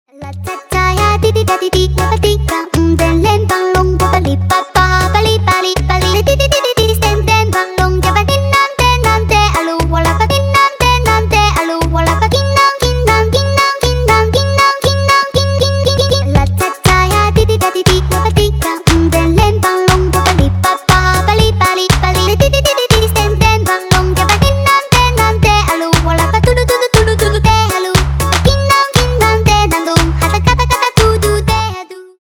Танцевальные # весёлые